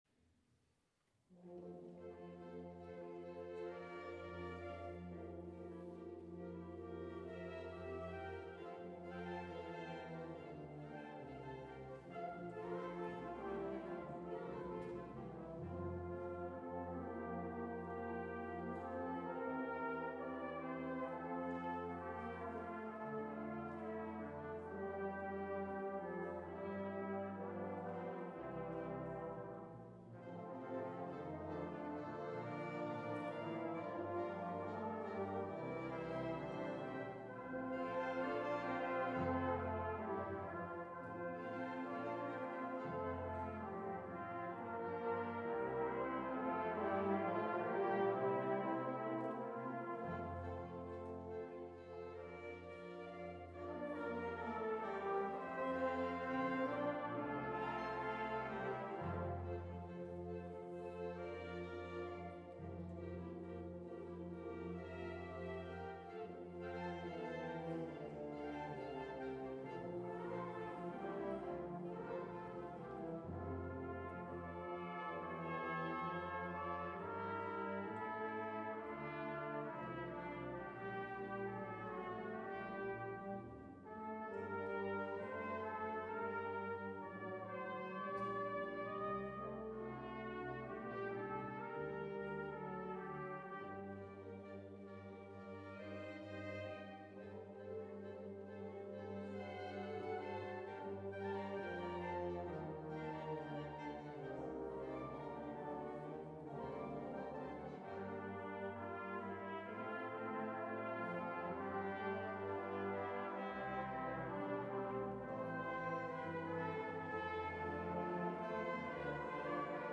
Erstes klassisches Weihnachtskonzert.
Kurz vor Ende unseres Jubiläumsjahres spielten wir unser erstes klassisches Weihnachtskonzert in der Pauluskirche in Hückeswagen. Mit dabei war der CVJM Hückeswagen, dessen Musik von der Empore der Kirche erklang.
8-Weihnachtskonzert-2012-Schafe-können-sicher-weiden.mp3